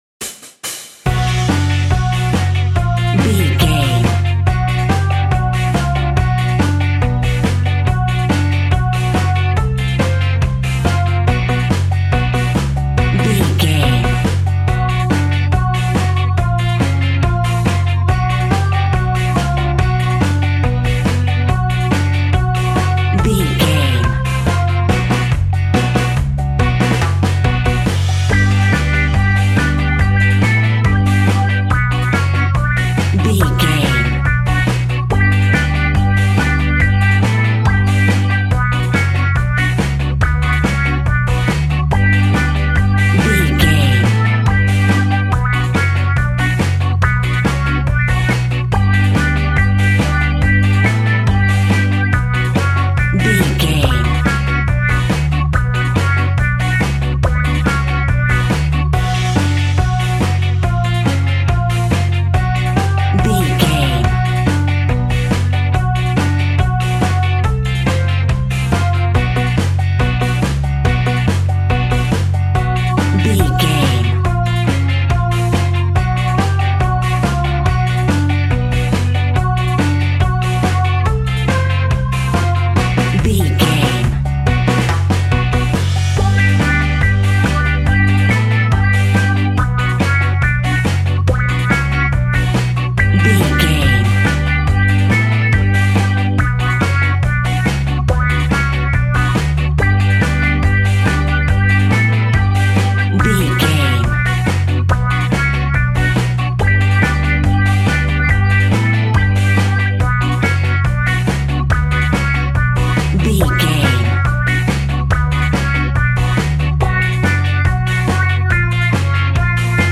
Aeolian/Minor
dub
laid back
chilled
off beat
drums
skank guitar
hammond organ
percussion
horns